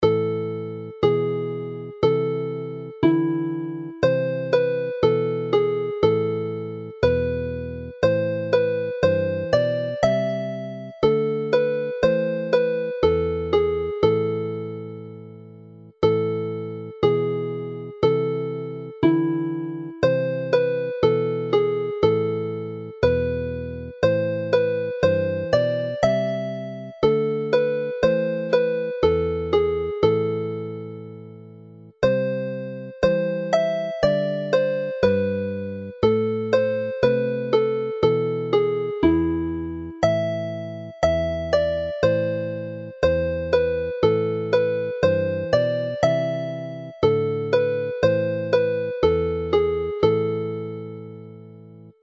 This set is founded on the traditional and well-loved Welsh hymn tune Hyder (Confidence / Faith) in a characteristically Welsh minor key.